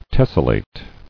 [tes·sel·late]